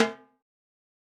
Perc.wav